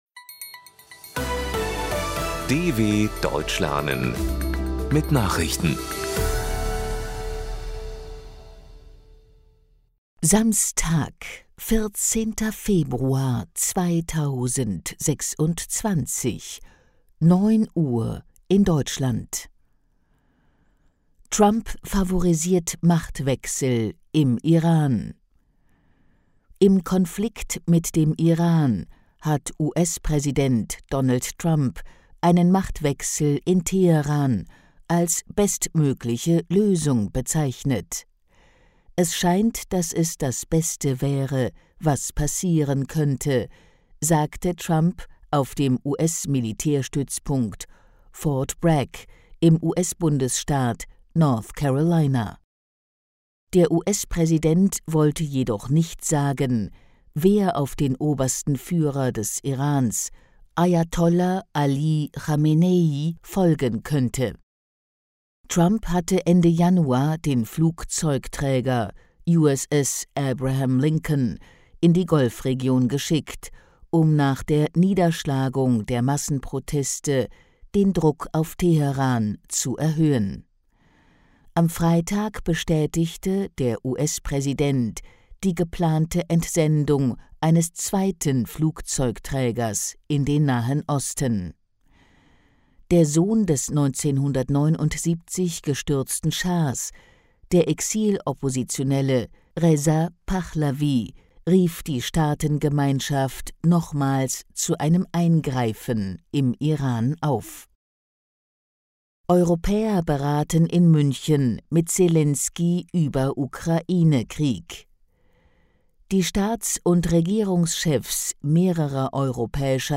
14.02.2026 – Langsam Gesprochene Nachrichten
Trainiere dein Hörverstehen mit den Nachrichten der DW von Samstag – als Text und als verständlich gesprochene Audio-Datei.